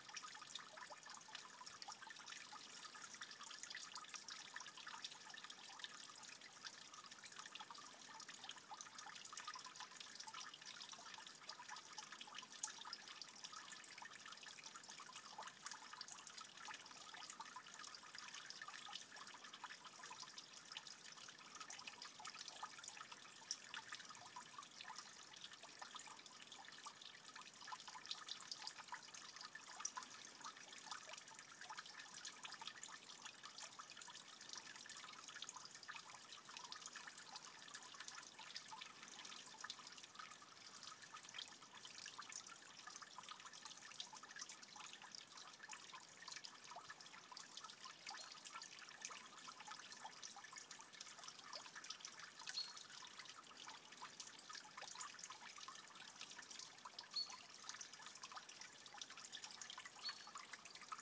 Soundcape water in ditch